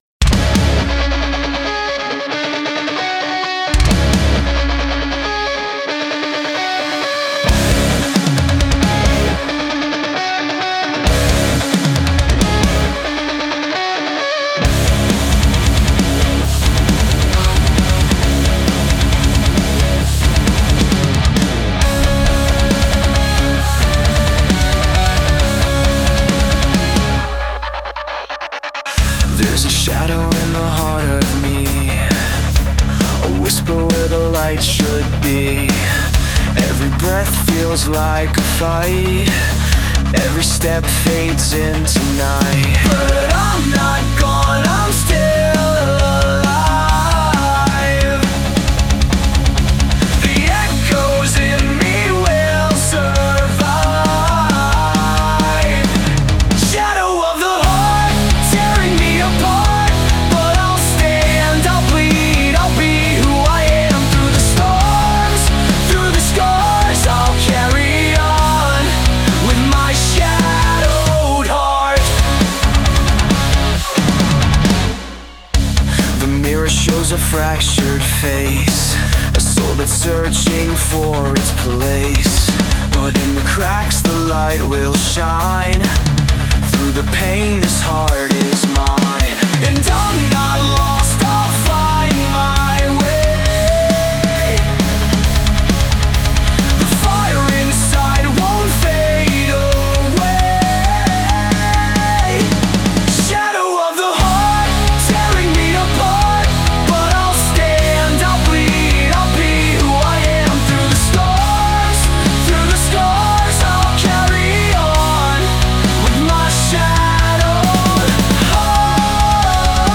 Genre: ROCK
heartfelt tribute band